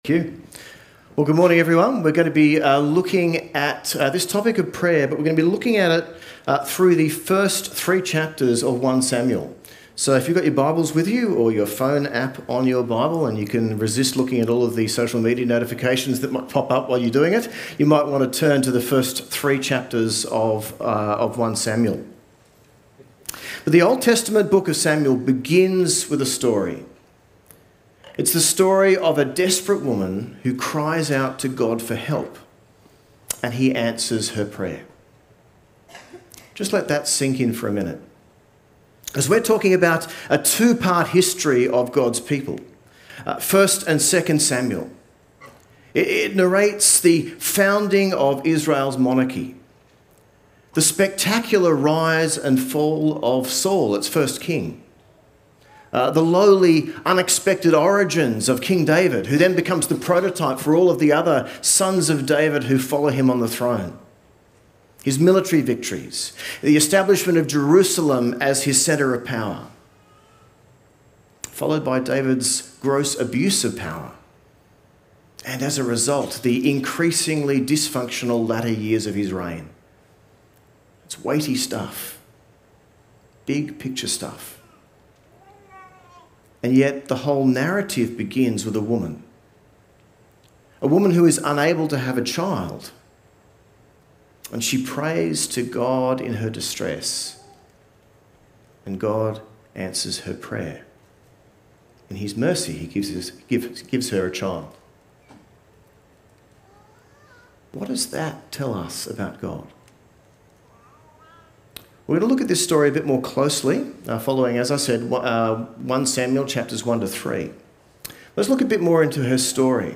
A message from the series "Time to Pray."